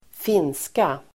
Uttal: [²f'in:ska]